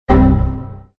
Windows XP Error.ogg